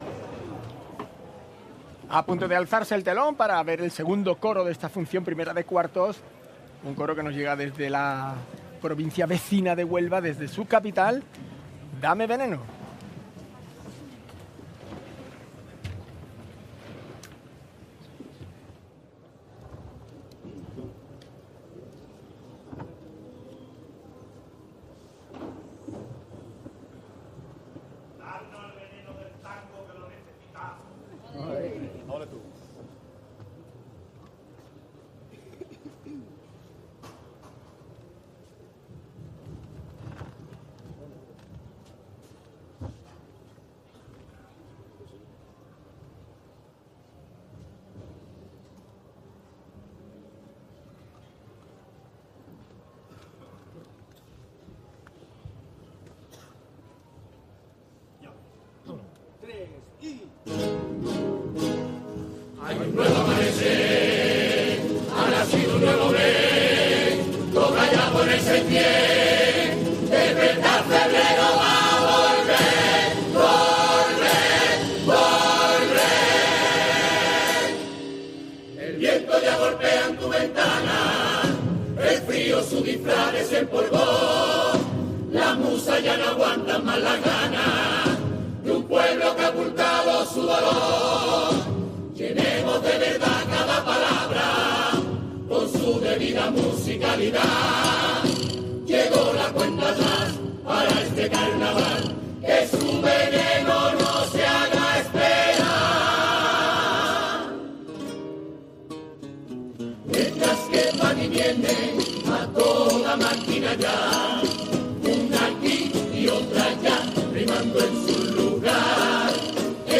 en la fase cuartos del COAC Carnaval de Cádiz 2026